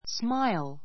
smáil ス マ イ る